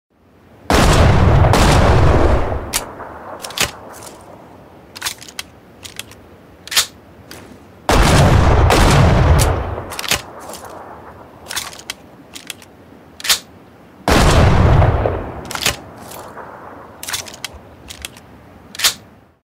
Tiếng súng Ngắn bắn